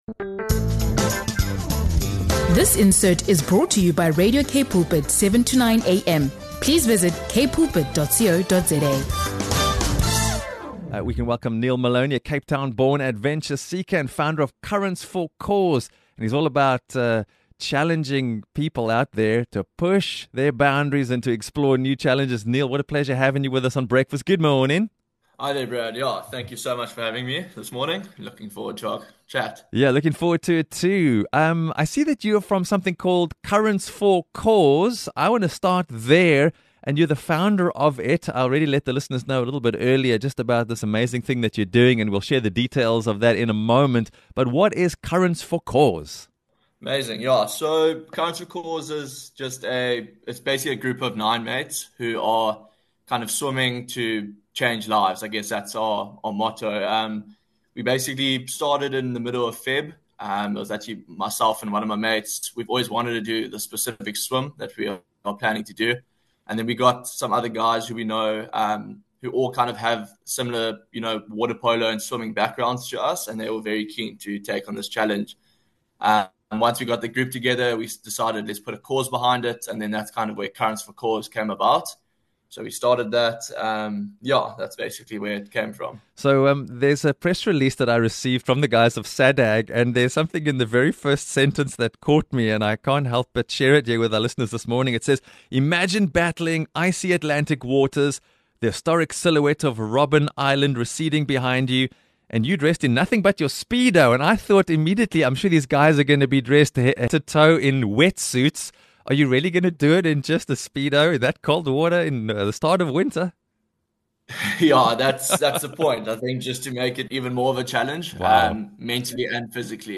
INTERVIEW - Cold Waters, Bold Hearts: How 9 Men Are Swimming for Mental Health Awareness